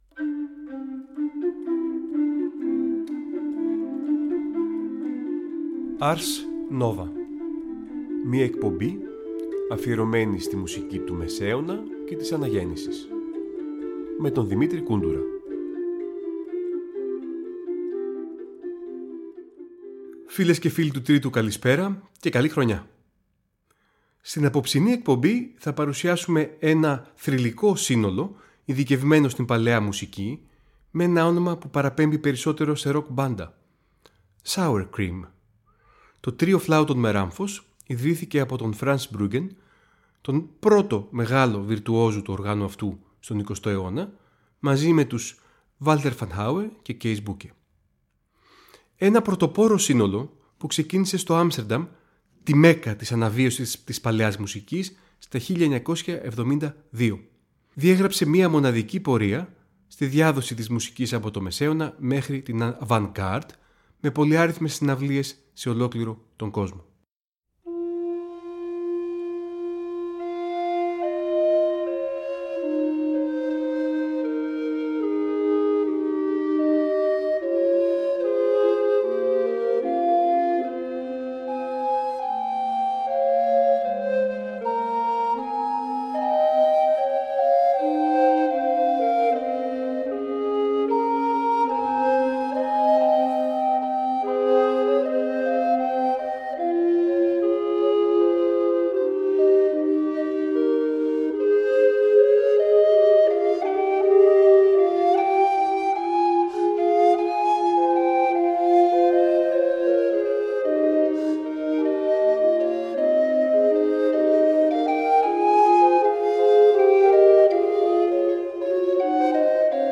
Νέα ωριαία μουσική εκπομπή του Τρίτου Προγράμματος που θα μεταδίδεται κάθε Τρίτη στις 19:00.